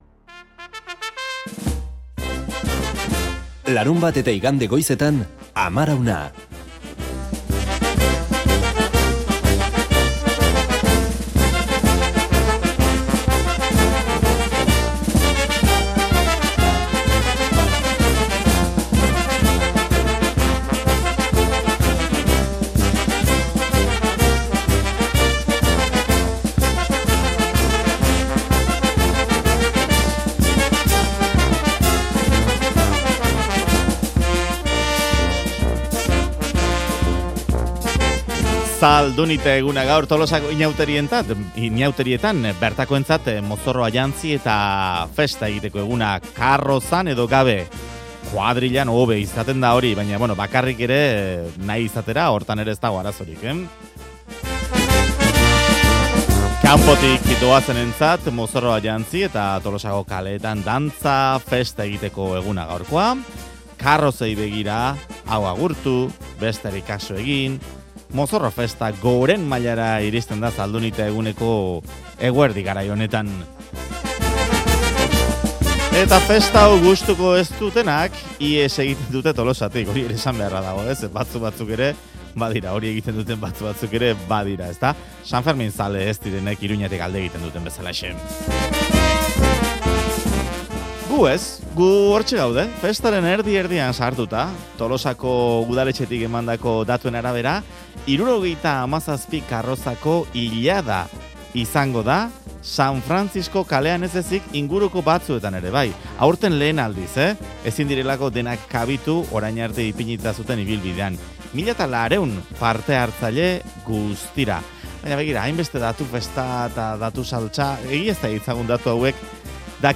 Audioa: Tolosako kaleetan mozorro artean "Aluminium" karrozako kideak
Audioa: Tolosako kaleetan ibili dira Amarauna saiokoak eta mozorro artean aurkitu dituzte "Aluminium" karrozako kideak. Lau aldiz lortu dute karroza lehiaketa irabaztea.